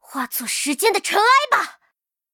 尘白禁区_安卡希雅辉夜语音_爆发.mp3